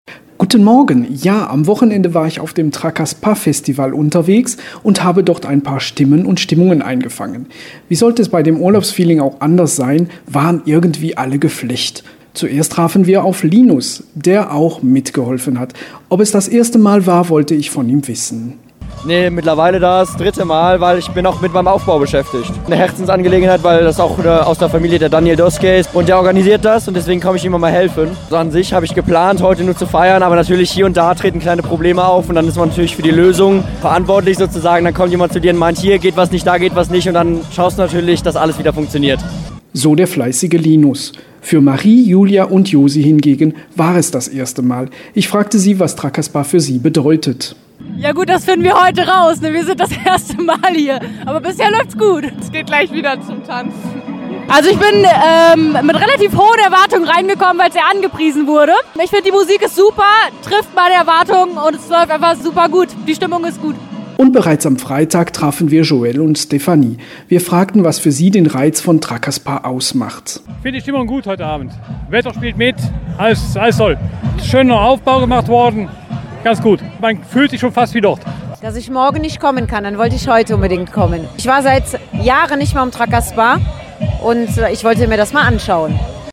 In der Eupener Industriezone stand am vergangenen Wochenende (1. + 2.7.2023) die diesjährige Auflage des Electronic Music Festivals „Trakasspa“ an. Rund um den Globus sind die Ostbelgischen-Festivaler schon gereist; 2023 lag die Destination in Südostasien.